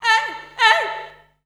Index of /90_sSampleCDs/Voices_Of_Africa/SinglePhrasesFemale